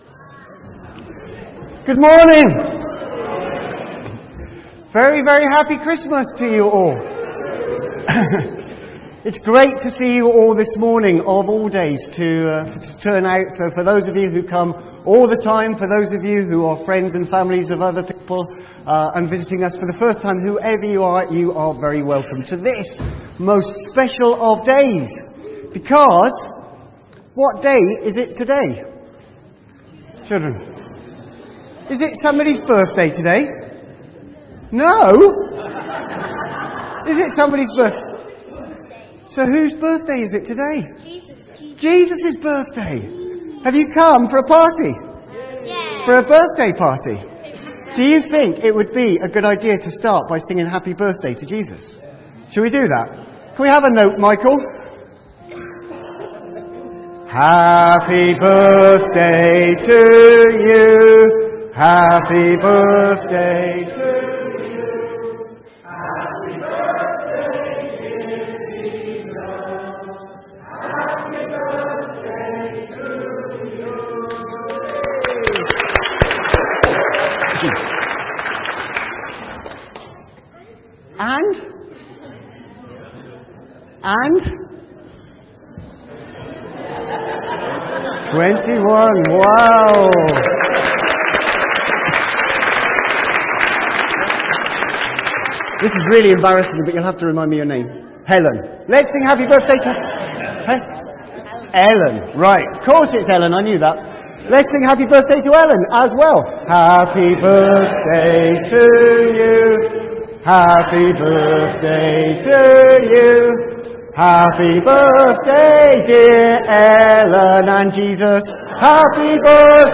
From Service: "10.00am Service"